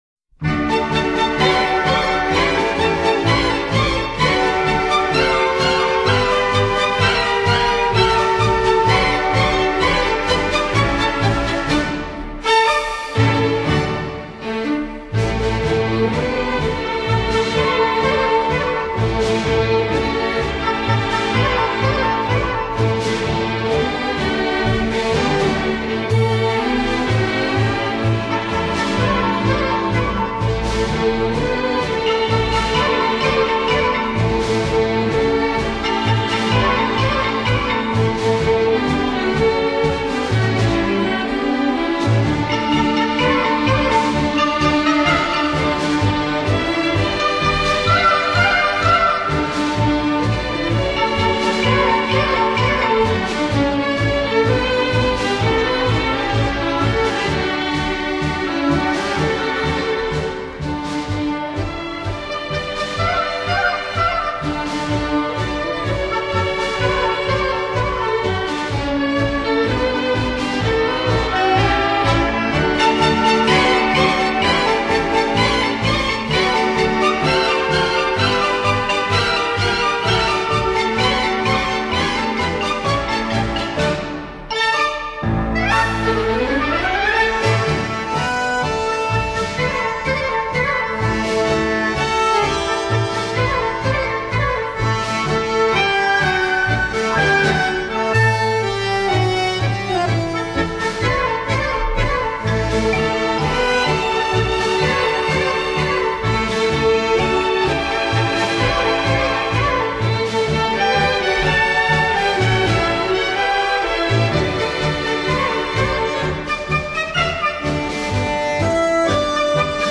Genre: Instrumental, Orchestra
他们标志性的声音来源于美妙的旋律、纯净清新的作品和愉悦放松的环境。